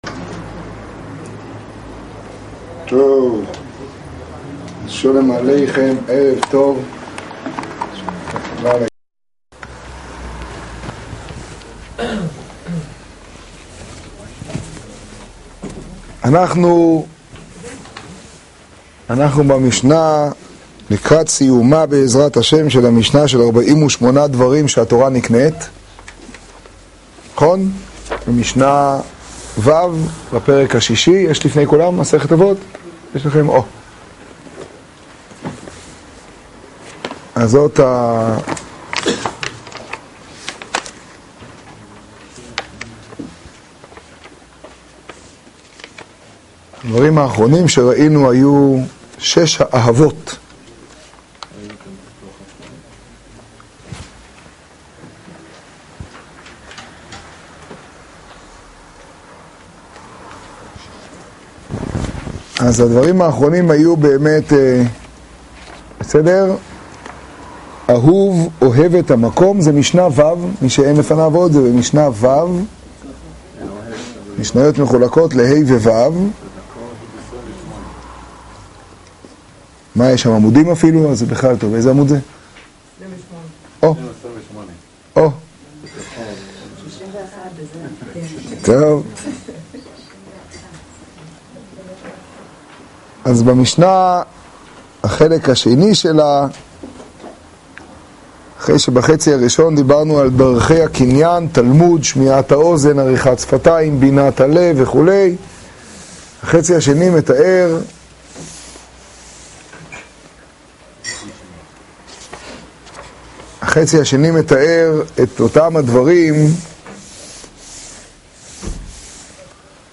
השיעור בחצור תשעג.